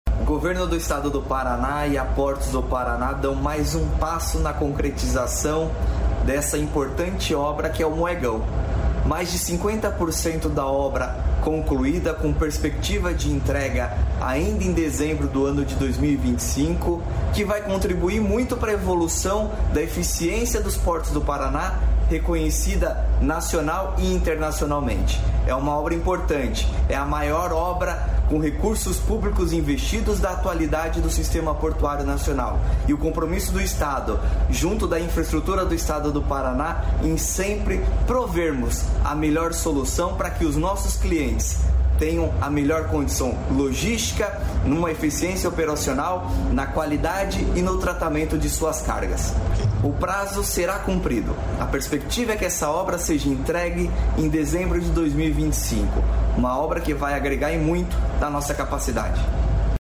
Sonora do diretor-presidente da Portos do Paraná, Luiz Fernando, sobre o avanço do Moegão